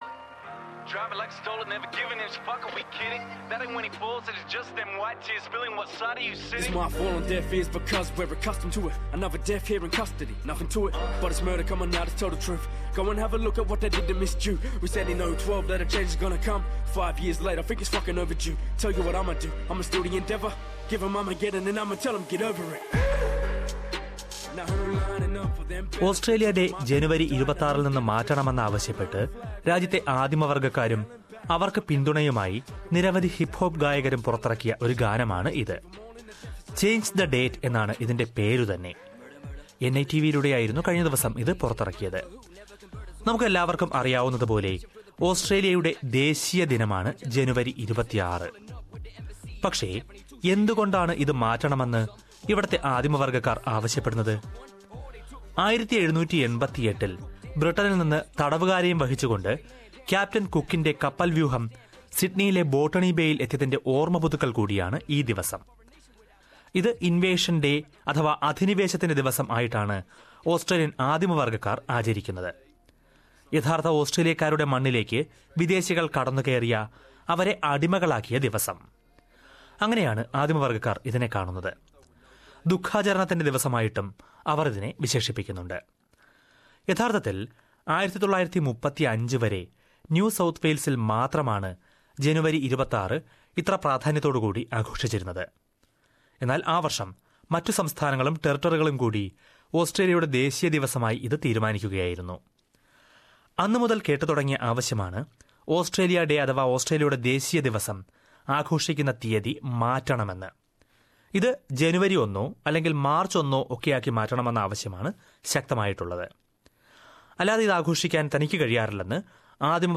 ഓസ്‌ട്രേലിയന്‍ ദേശീയ ദിനമായ ഓസ്‌ട്രേലിയ ഡേ ജനുവരി 26ല്‍ നിന്ന് മാറ്റണമെന്ന ആവശ്യം രാജ്യത്തെ ആദിമവര്‍ഗ്ഗക്കാര്‍ കൂടുതല്‍ ശക്തമാക്കുകയാണ്. അതോടൊപ്പം, ഓസ്‌ട്രേലിയ റിപ്പബ്ലിക്കാകണമെന്ന ആവശ്യവും ഈ ഓസ്‌ട്രേലിയ ഡേയില്‍ കൂടുതല്‍ സജീവമാകുകയാണ്. എന്തുകൊണ്ട് ഇത്തരം ആവശ്യങ്ങള്‍ ഉയരുന്നു എന്ന് കേള്‍ക്കാം, ഈ റിപ്പോര്‍ട്ടില്‍ നിന്ന്...